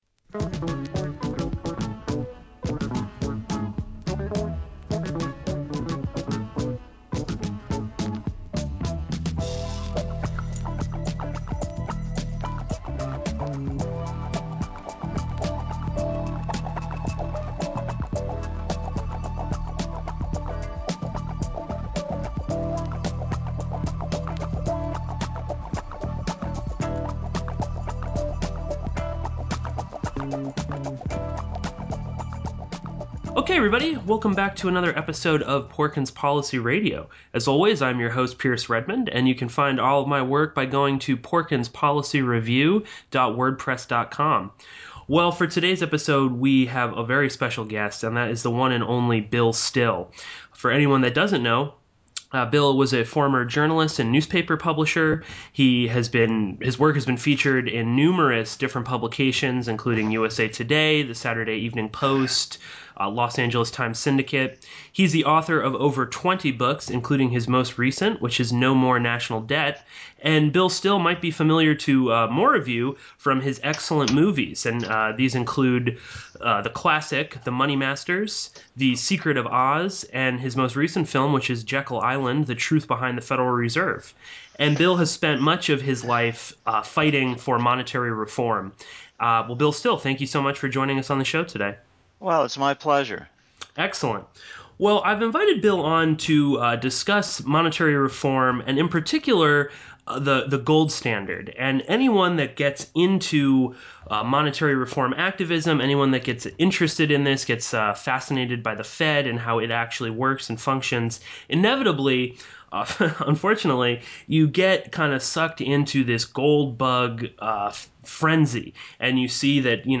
This is a very important conversation for anyone concerned about the largest scandal in American history, which is of course the destruction of our power to create and control our money.